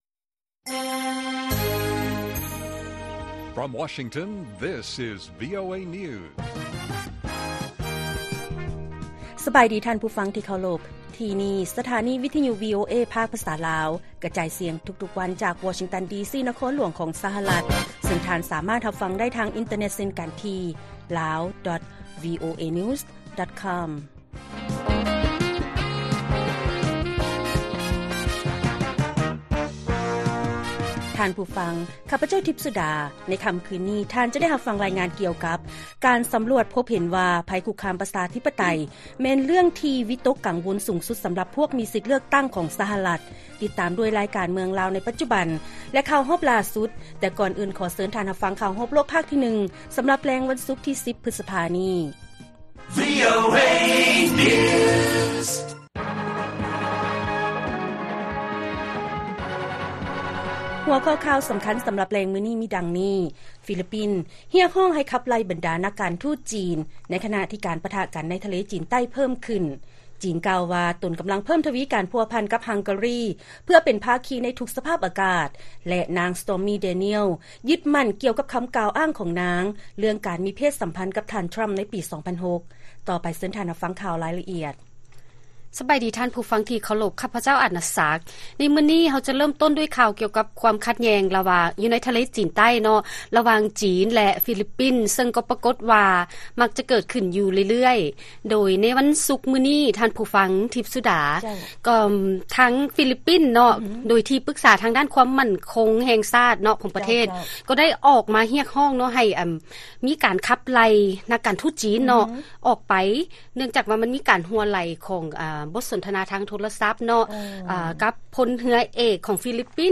ວີໂອເອພາກພາສາລາວ ກະຈາຍສຽງທຸກໆວັນ, ຫົວຂໍ້ຂ່າວສໍາຄັນໃນມື້ນີ້ ມີດັ່ງນີ້: 1.